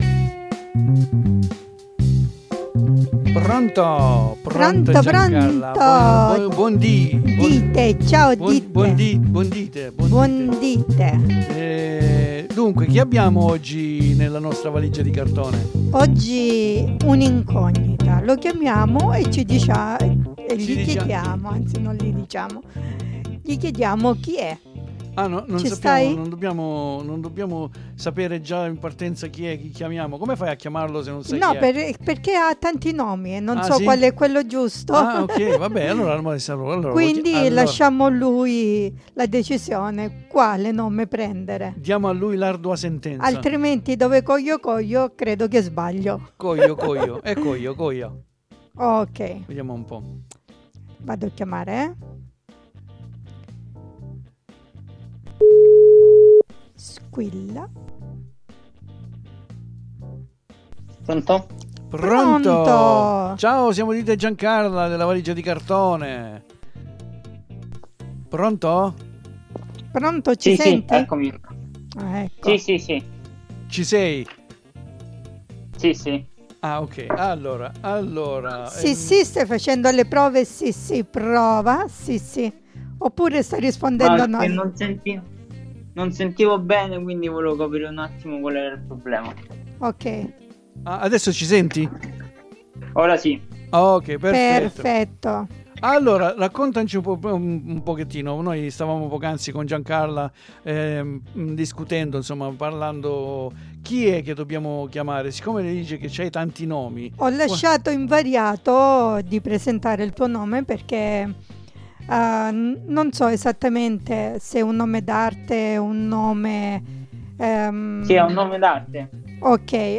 NON VI SVELO MOLTO PERCHÉ VI INVITO AD ASCOLTARE QUESTA CHIACCHERATA, MOLTO INTERESSANTE!